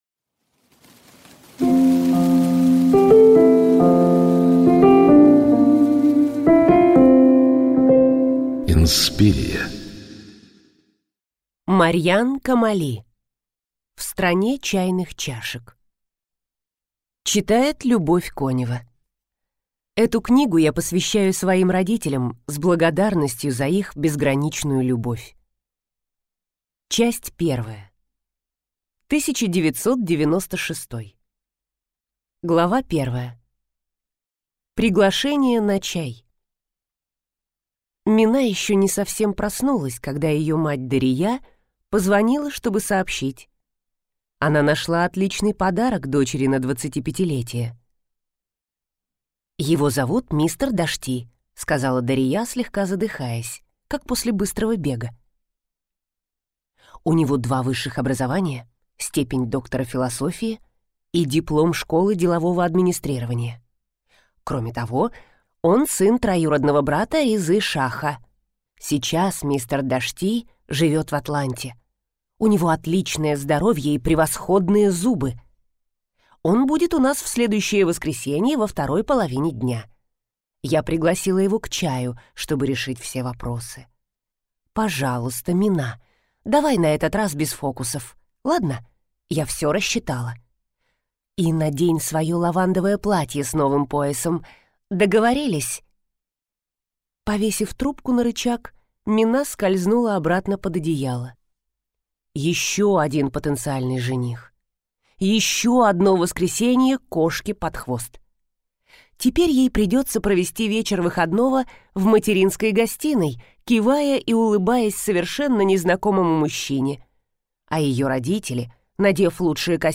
Аудиокнига В стране чайных чашек | Библиотека аудиокниг